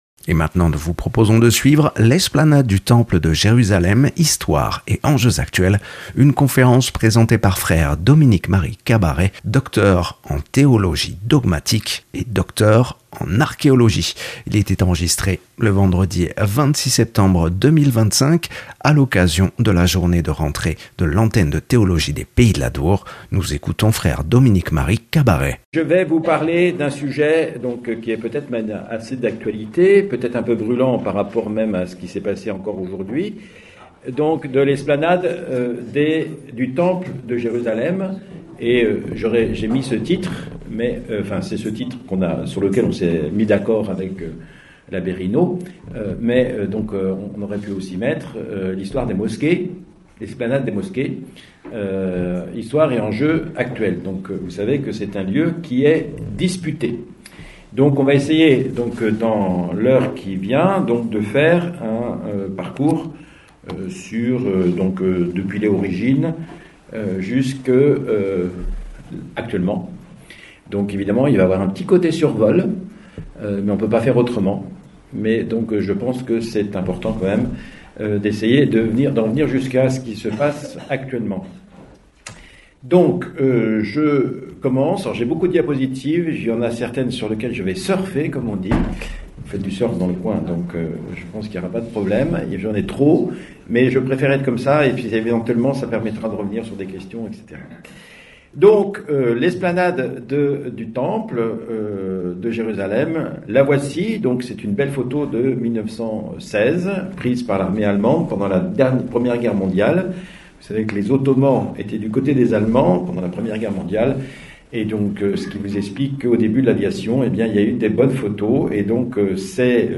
Conférence
enregistré le 26 septembre 2025 à la maison diocésaine de Bayonne.